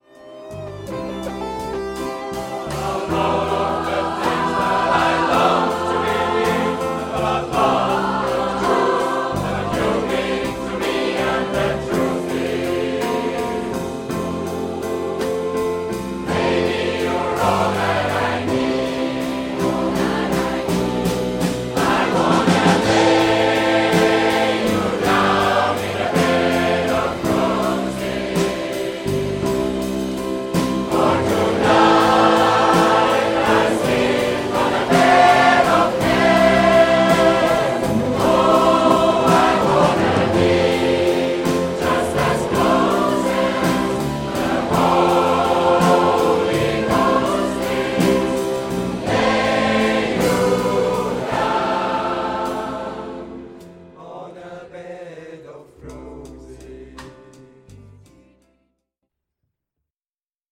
Band
SMATB